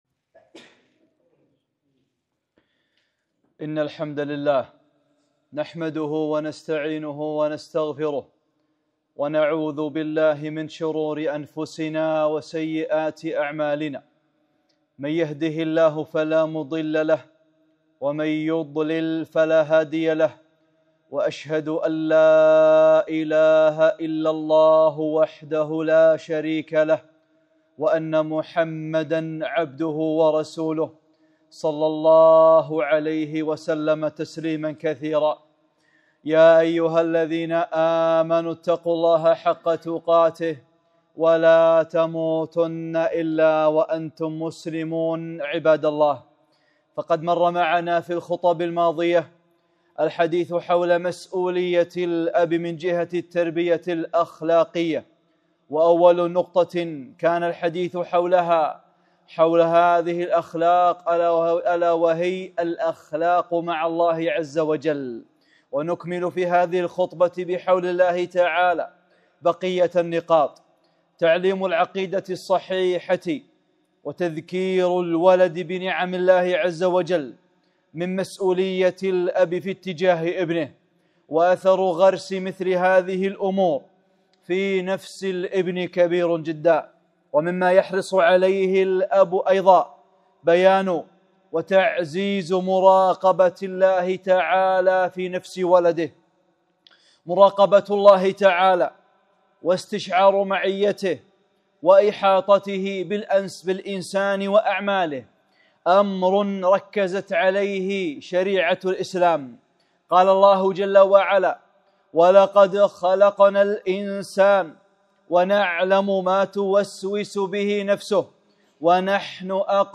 (27) خطبة - مراقبة الله | أمور هامة متعلقة بالآباء والأمهات